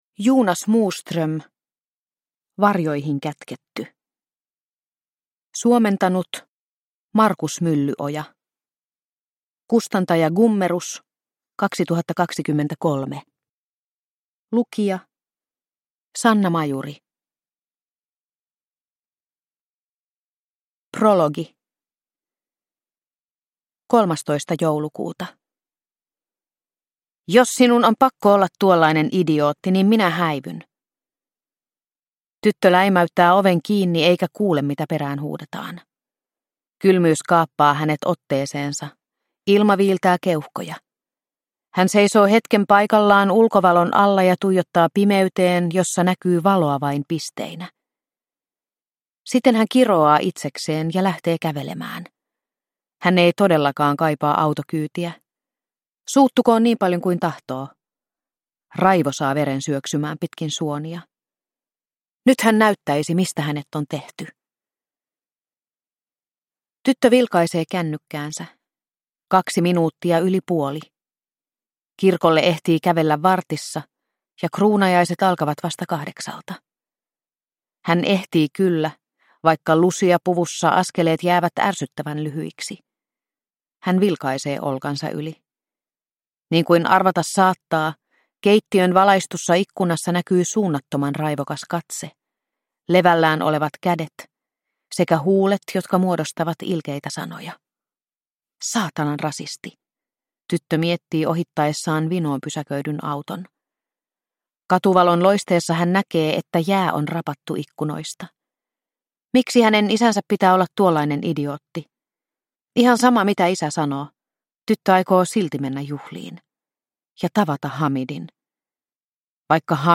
Varjoihin kätketty – Ljudbok – Laddas ner